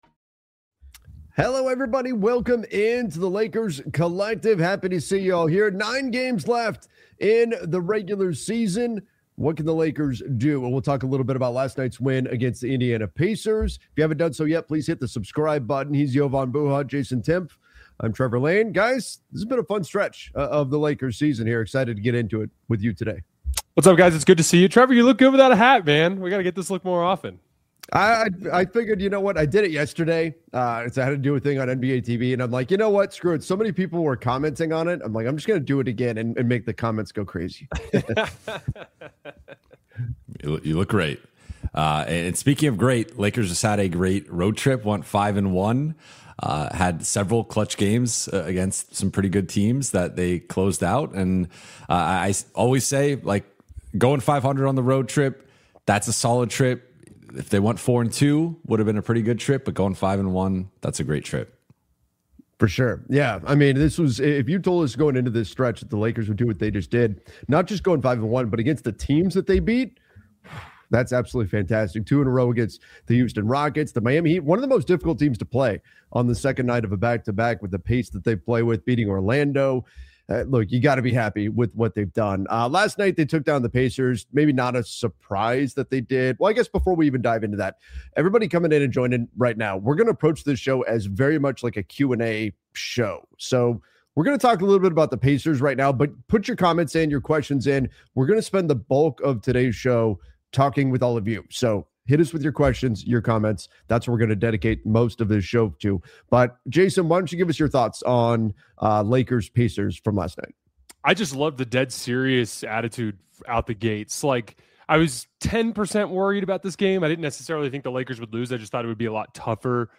a weekly Los Angeles Lakers roundtable
We go live every Thursday at 10:00 AM PT with sharp analysis, smart X’s-and-O’s talk, and honest conversations about all things Lakers — from game breakdowns and player development to cap moves and playoff paths.